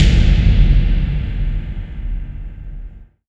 VEC3 FX Reverbkicks 19.wav